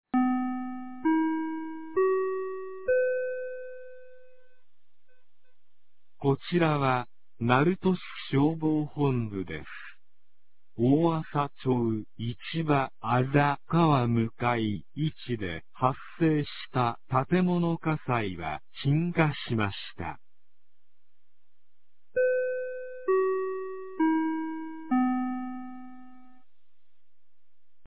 2026年04月10日 10時14分に、鳴門市より大麻町-市場、大麻町-牛屋島、大麻町-松村へ放送がありました。